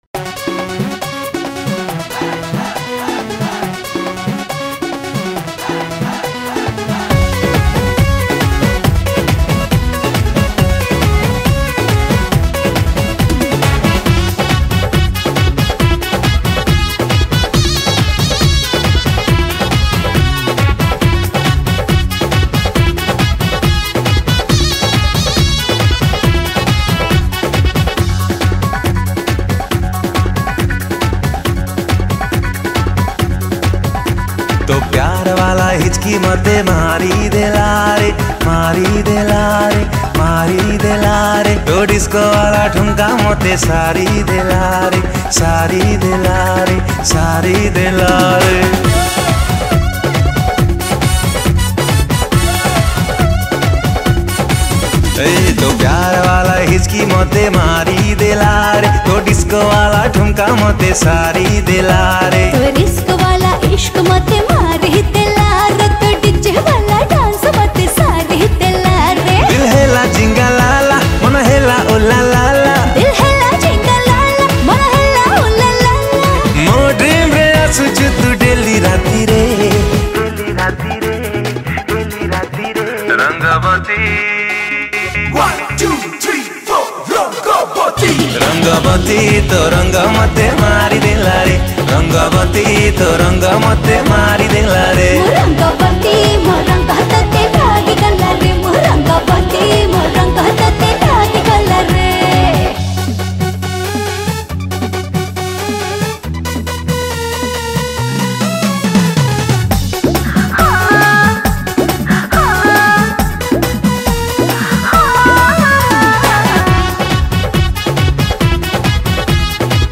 ♪ Keyboard